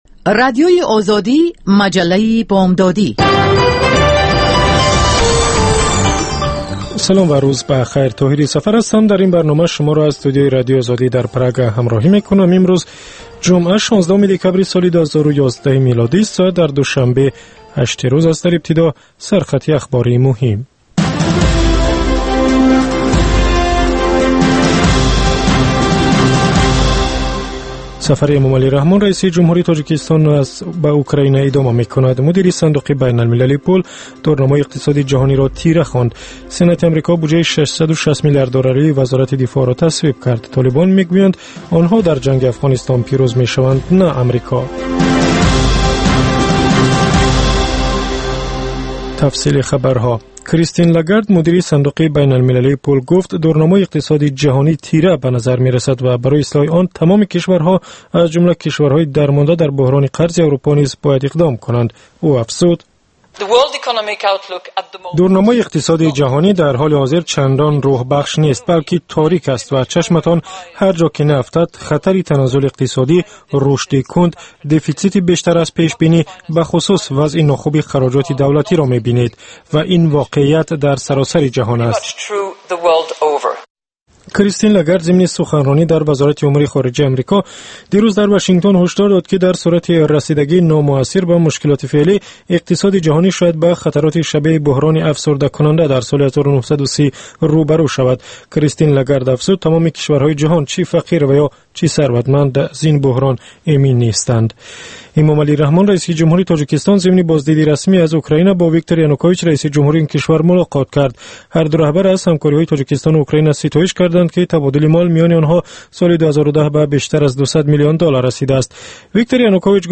Фишурдаи охирин ахбори ҷаҳон, гузоришҳо аз Тоҷикистон, гуфтугӯ ва таҳлилҳо дар барномаи бомдодии Радиои Озодӣ.